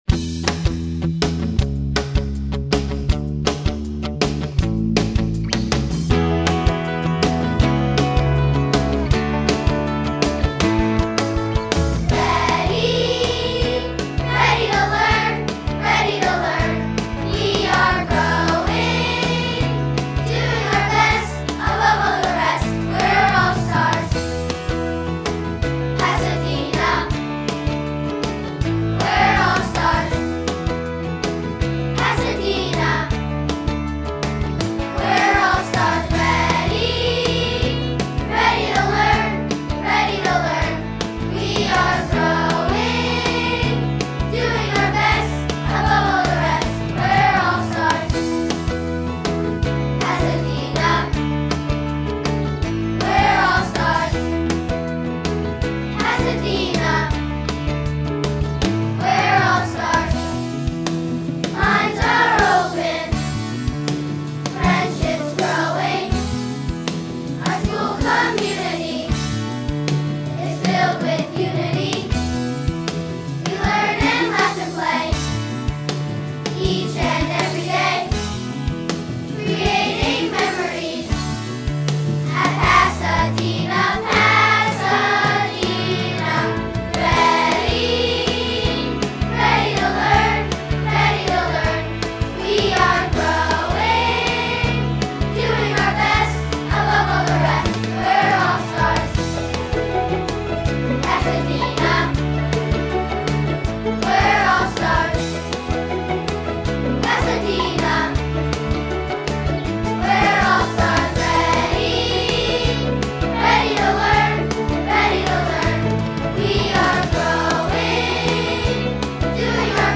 Performed by our amazing students!
Pasadena-School-Song-Remix.mp3